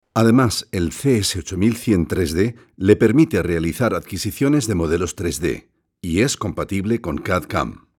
Zwischen Spanien und Deutschland zweisprachig hin und her pendelnd lernte er 2 Sprachen akzentfrei zu sprechen.
Sprechprobe: Industrie (Muttersprache):